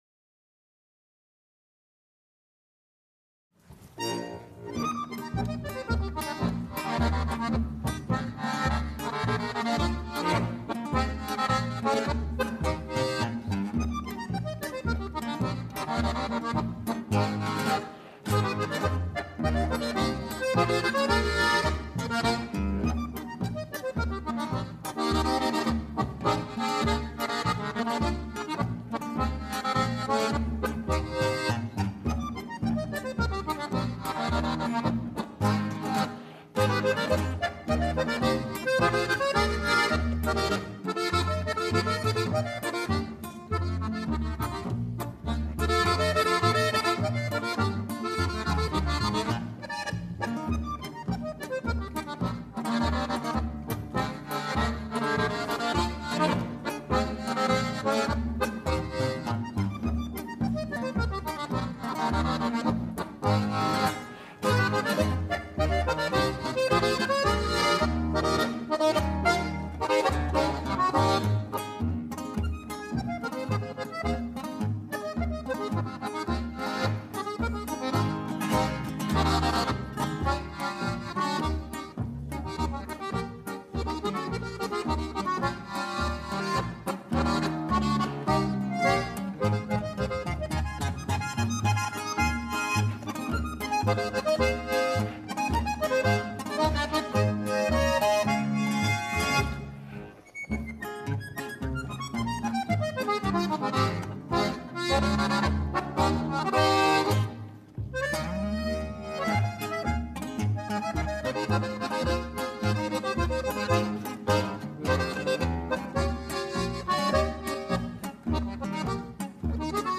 Ein mitreißendes Stück!